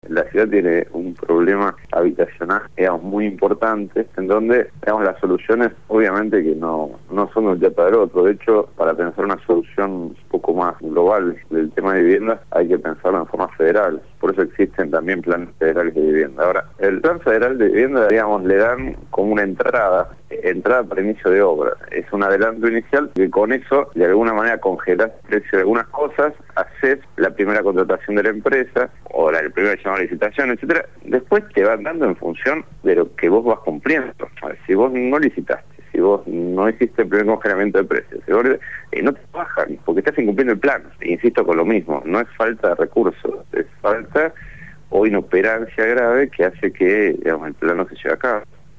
Luego fue el turno de entrevistar al Legislador Porteño Diego Kravetz, quién dio cuenta del gravísimo problema habitacional